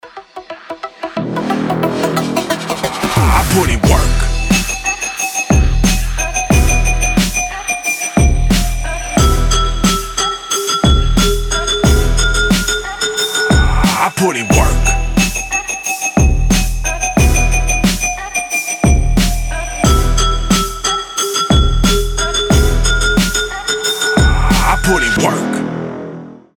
Trap
качающие
Bass
Крутой проигрыш